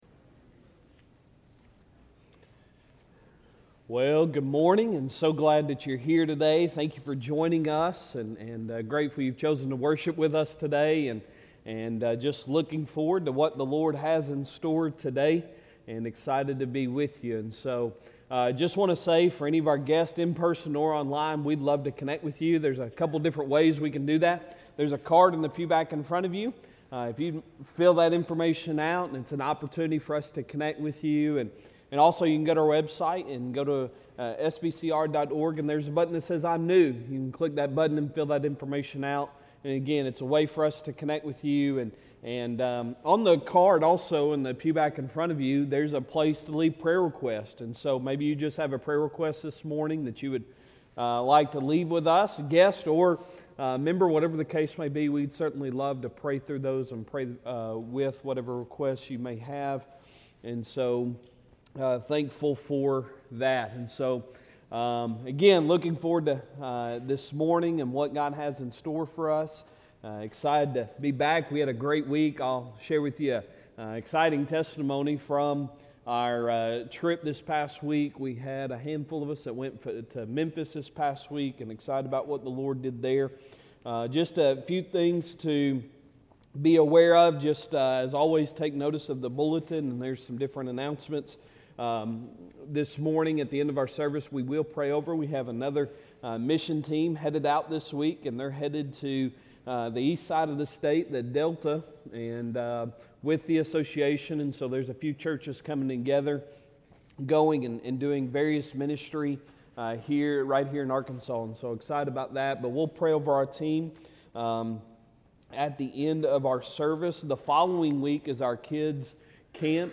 Sunday Sermon July 16, 2023